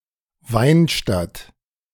Weinstadt (German pronunciation: [ˈvaɪnˌʃtat]
De-Weinstadt.ogg.mp3